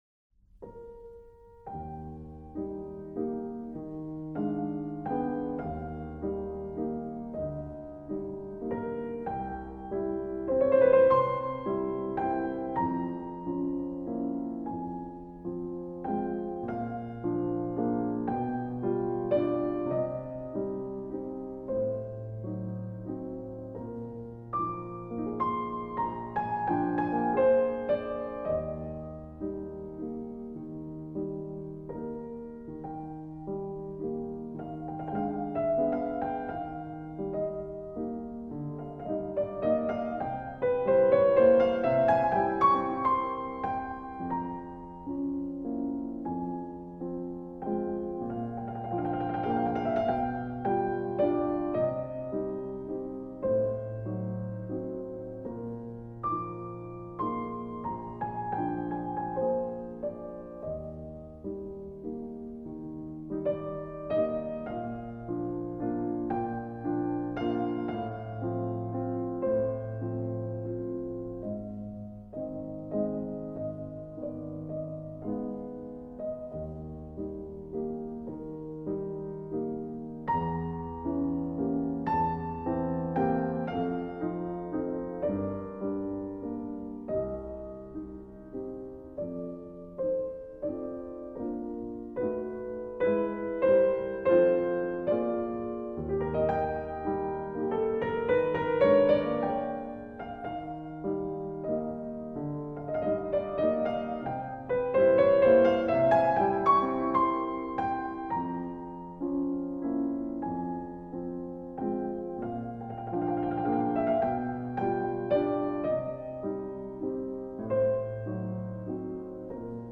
Chopin-Nocturne-in-E-flat-major.mp3